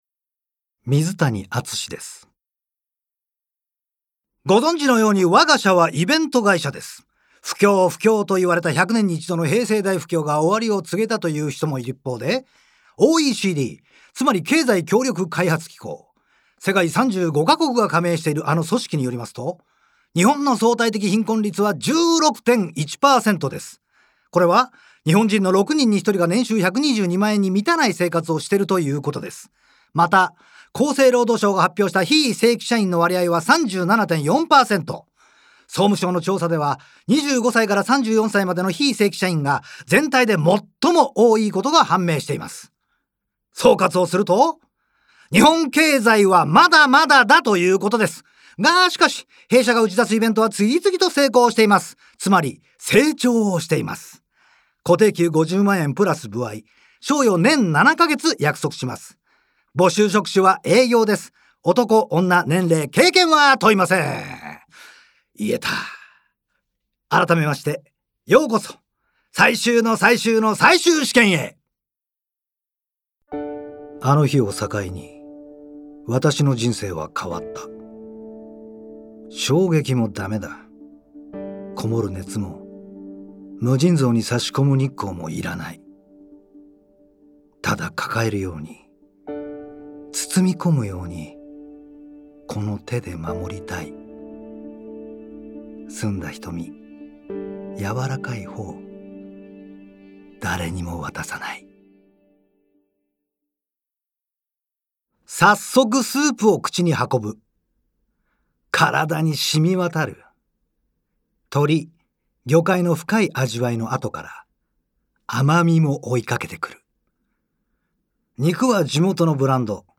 • 俳優
VOICE SAMPLE